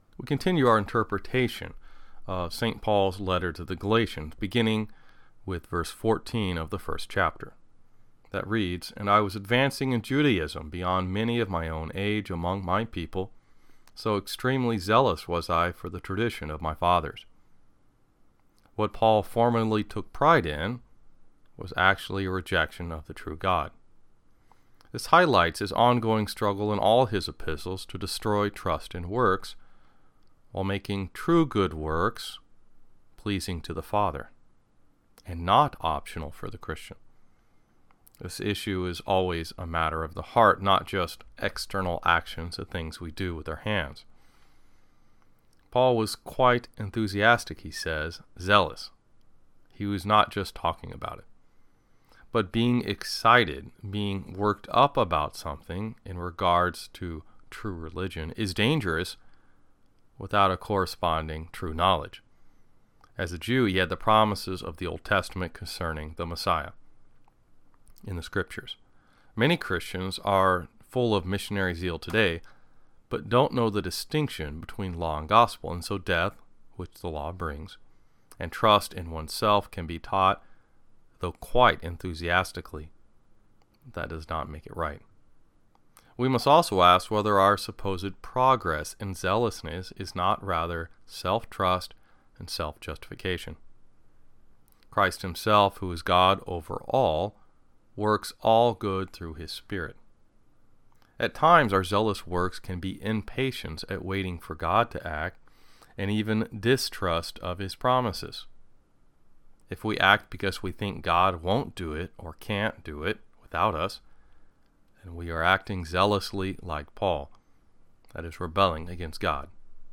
This study was recorded in 15 hours of audio for KNNA 95.7 The Cross, which is associated with Good Shepherd Lutheran Church, Lincoln, Nebraska